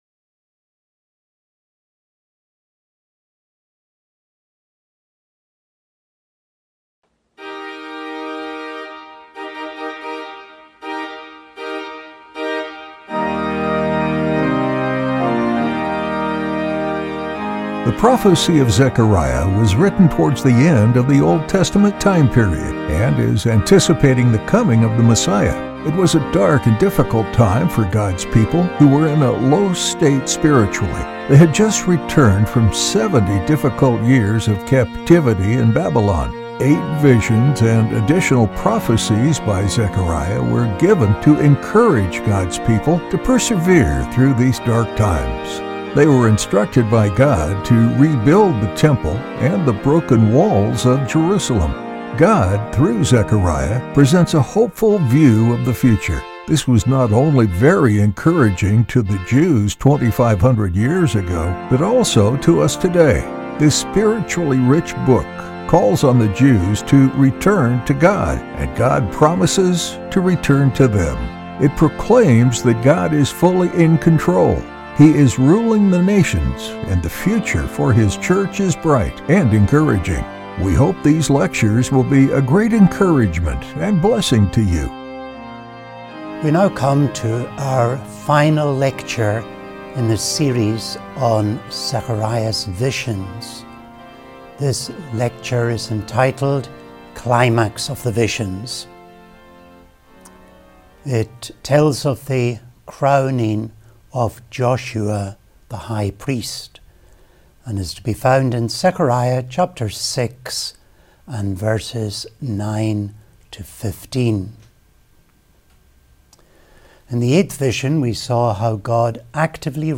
We now come to our final lecture in this series on Zechariah’s visions. This lecture is entitled, “Climax of the Visions,” and it tells of the crowning of Joshua, and it’s to be found in Zechariah, chapter 6, and verses 9 to 16.